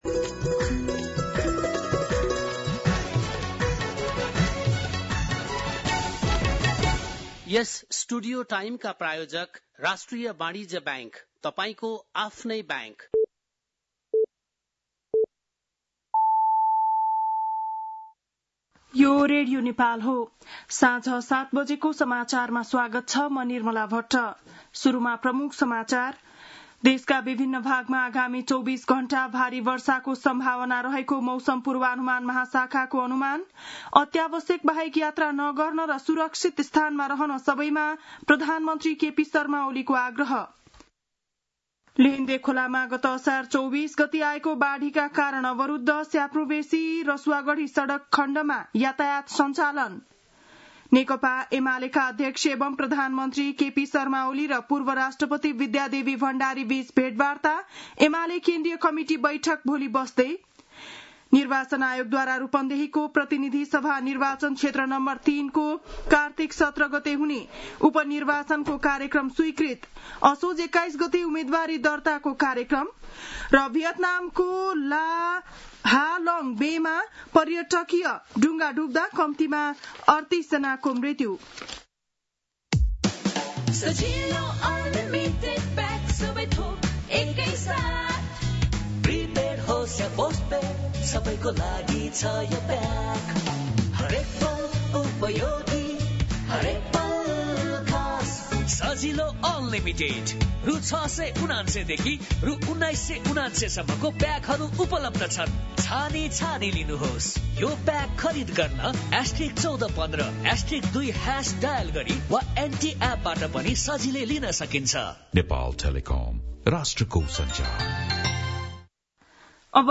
बेलुकी ७ बजेको नेपाली समाचार : ४ साउन , २०८२
7-pm-nepali-news-4-4.mp3